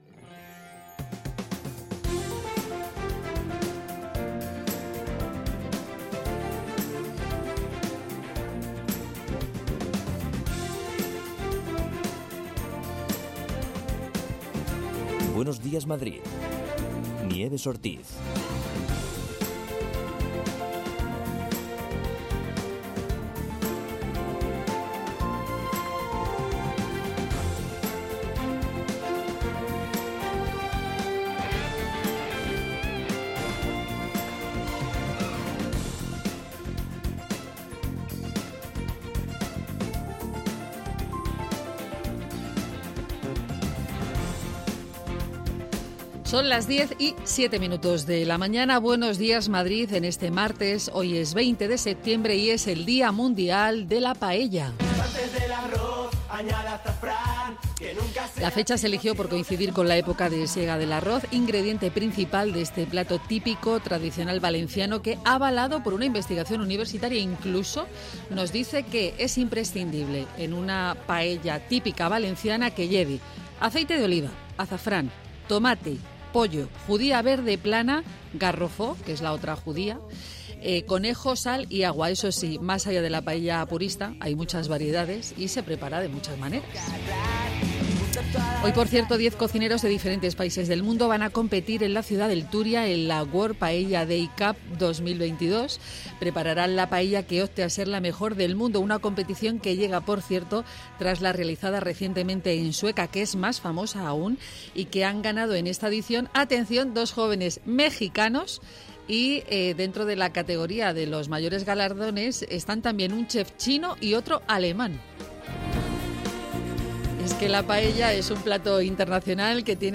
Tres horas más de radio donde se habla de psicología, ciencia, cultura, gastronomía, medio ambiente y consumo.